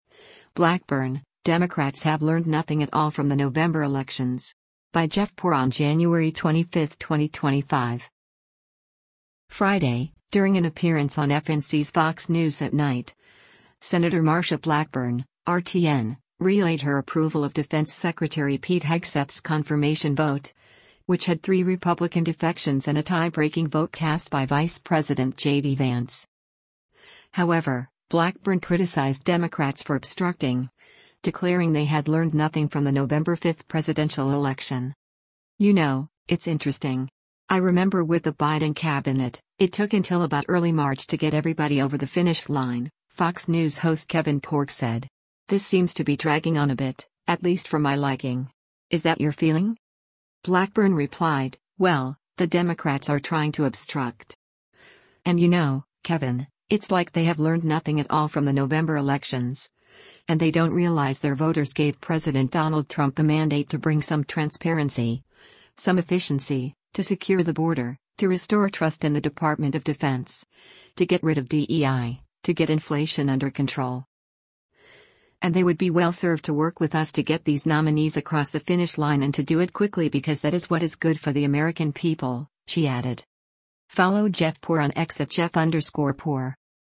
Friday, during an appearance on FNC’s “Fox News @ Night,” Sen. Marsha Blackburn (R-TN) relayed her approval of Defense Secretary Pete Hegseth’s confirmation vote, which had three Republican defections and a tie-breaking vote cast by Vice President JD Vance.